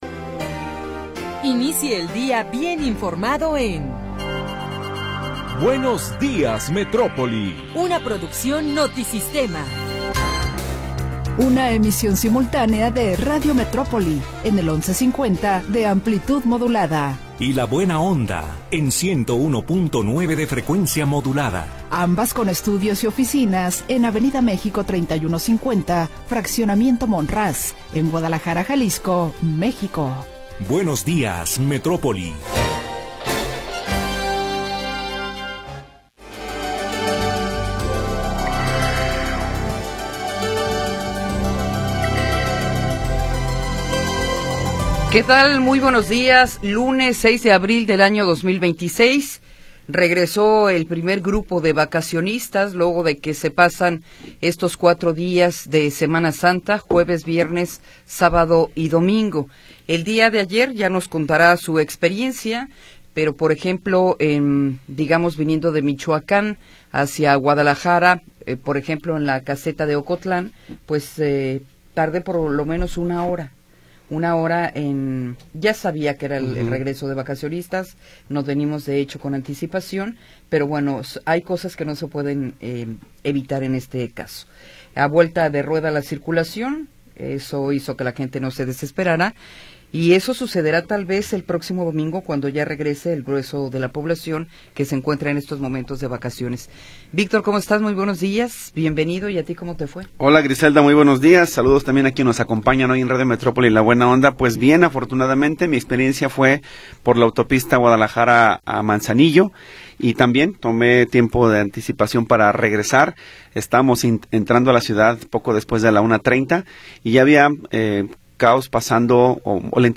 Primera hora del programa transmitido el 6 de Abril de 2026.